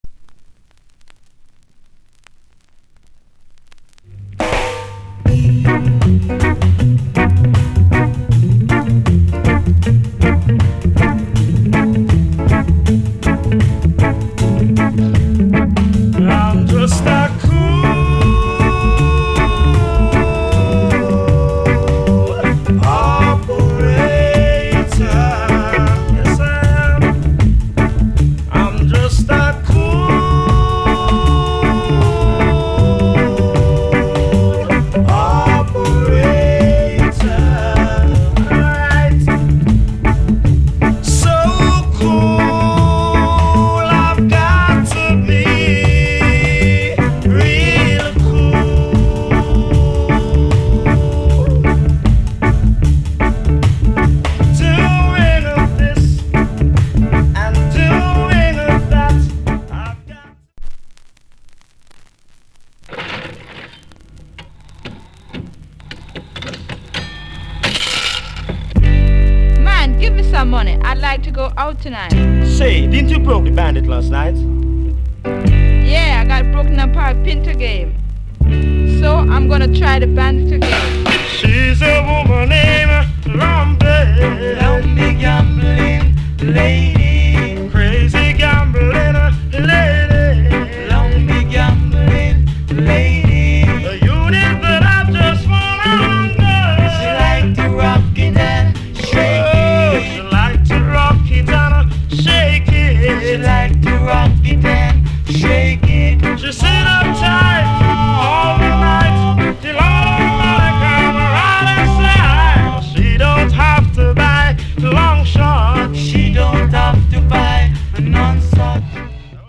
* Very Rare Rock Steady Vocal.